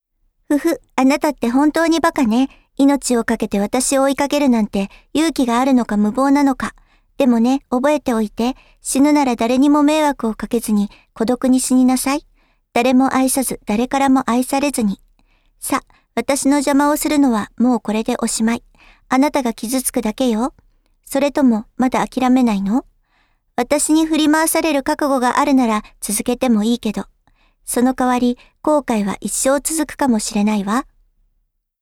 感情を込めずに音読します。
【素読み】